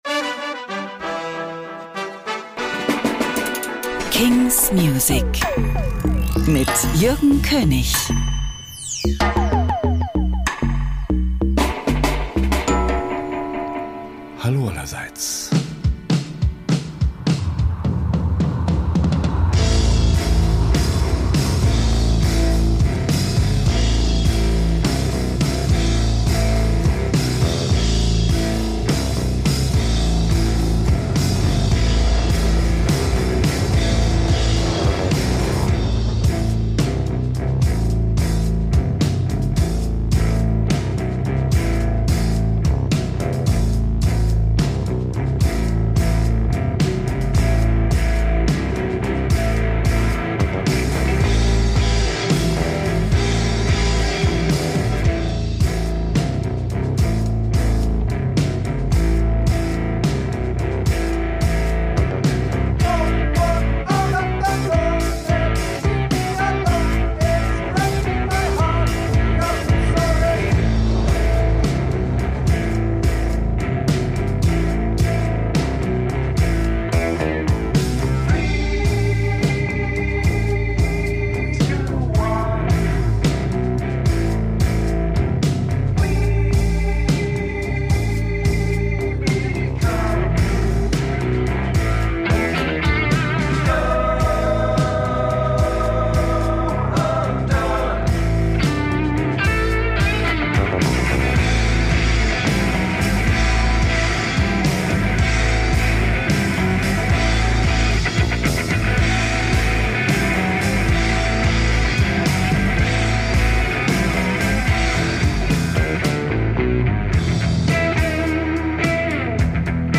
great, new indie & alternative releases